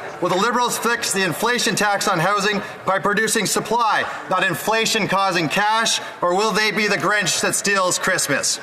MP Ryan Williams speaks on housing and inflation in House of Commons